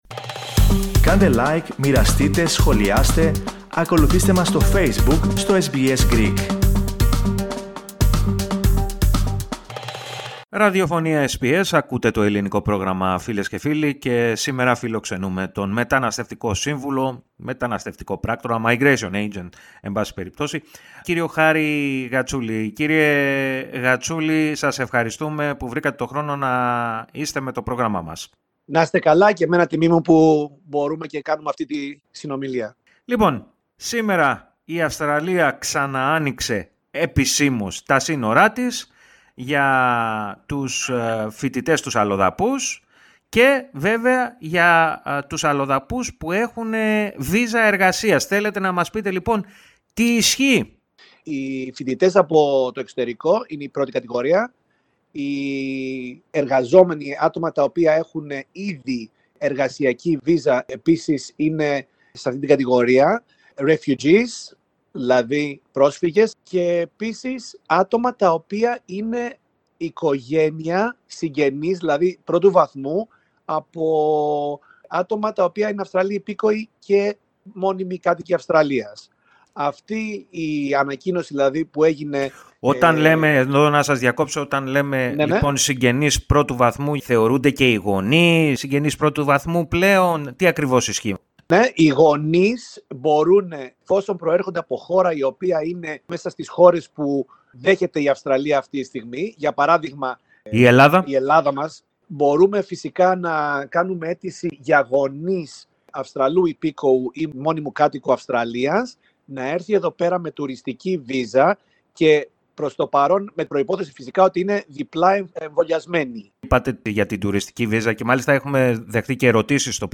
Άνοιξαν, από σήμερα, τα διεθνή σύνορα της Αυστραλίας, για κατόχους εργασιακής βίζας, και ξένους φοιτητές, και με αυτήν την αφορμή, μίλησε στο Ελληνικό Πρόγραμμα της ραδιοφωνίας SBS, ο ομογενής μεταναστευτικός σύμβουλος,